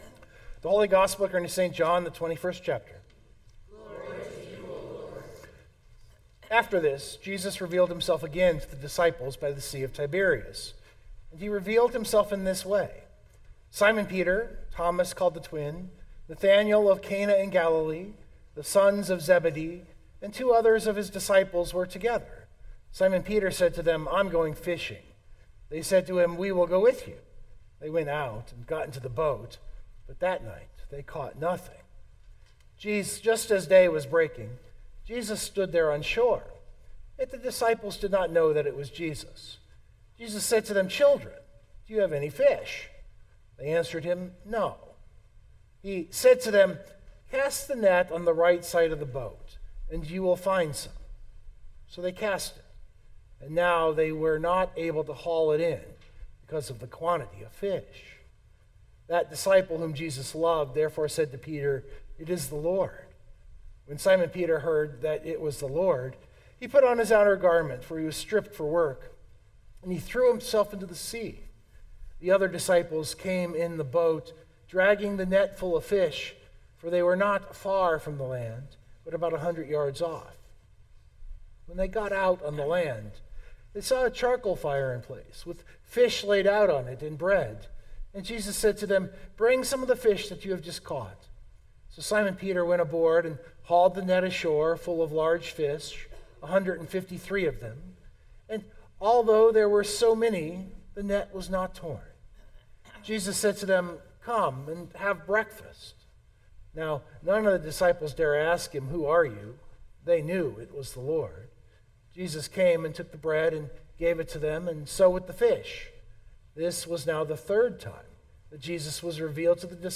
Recording note: the first 15 secs of the actual sermon sound bad. My microphone was muted at the board. So I amplified the ambient recording. After 15 secs the mute was taken off and it sounds much better.